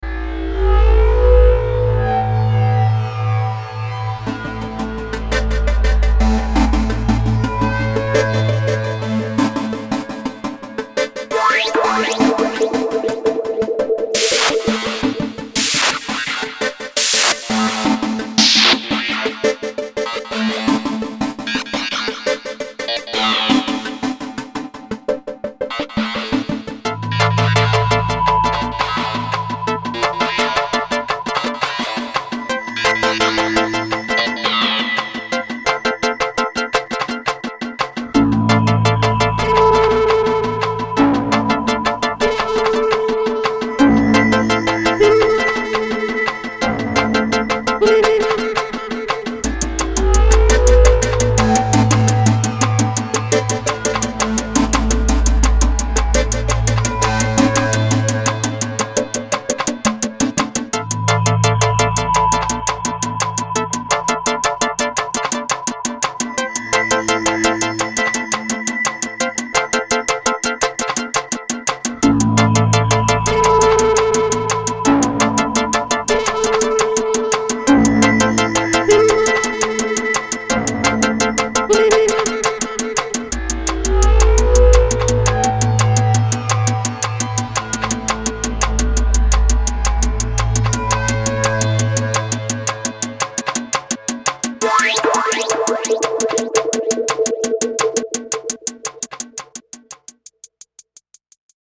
soundscape sound scape fx special fx